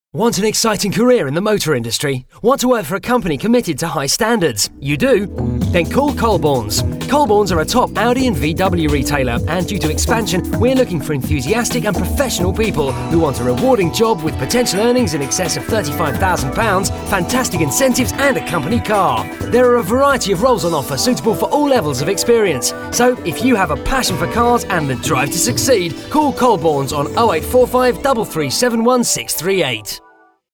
"Radio Advert"